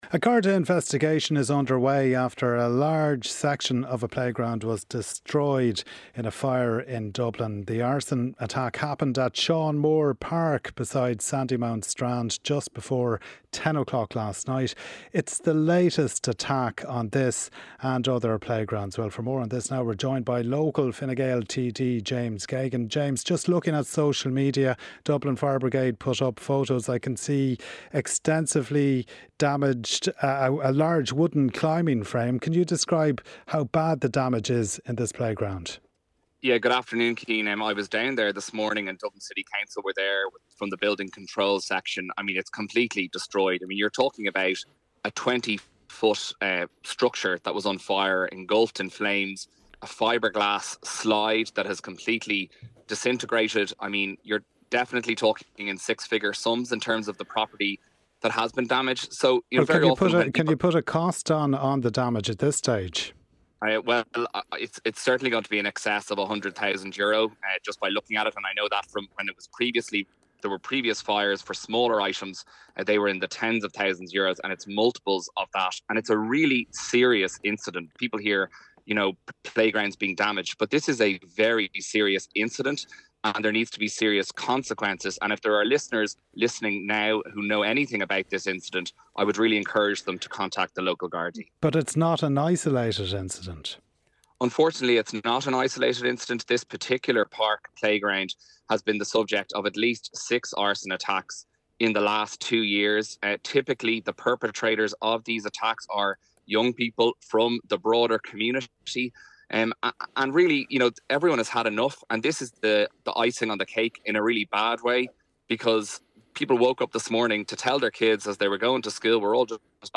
James Geoghegan, Fine Gael TD, discusses an arson attack at Sean Moore Park beside Sandymount Strand.